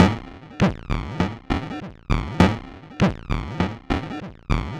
Index of /90_sSampleCDs/Transmission-X/Percussive Loops
tx_perc_100_horrible.wav